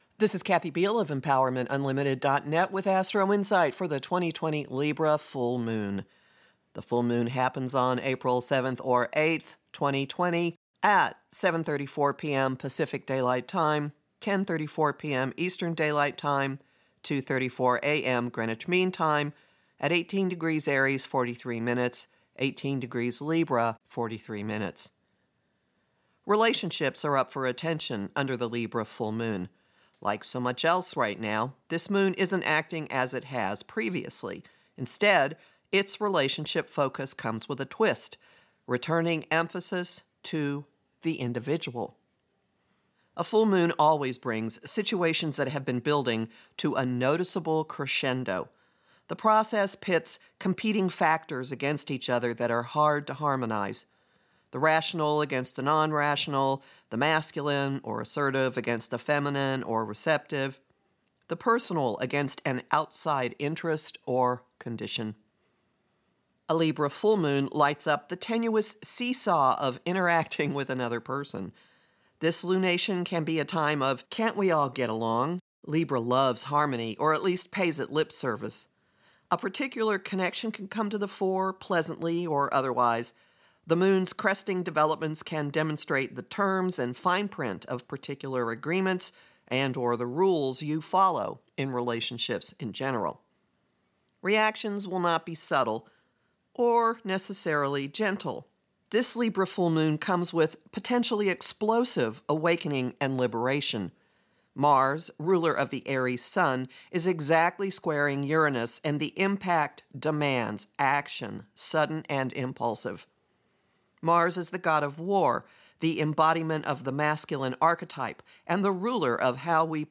To listen to Astrologer